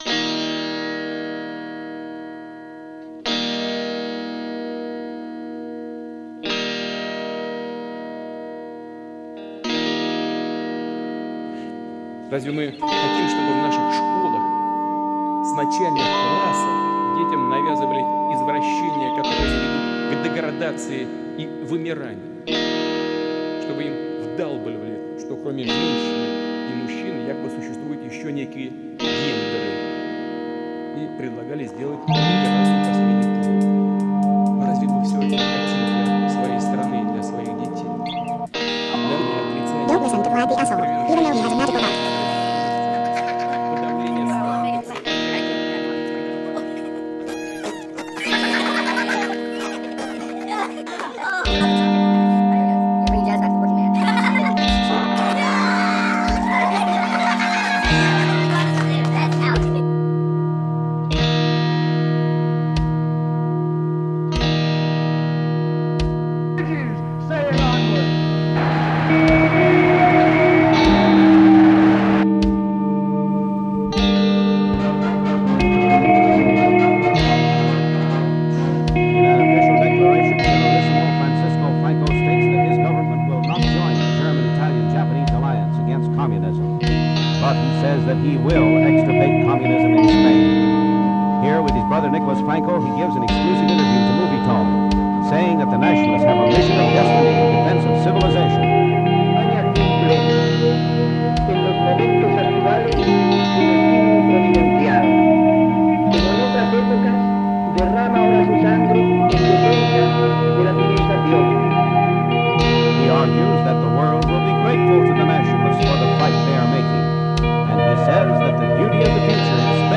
The demos
audio collage album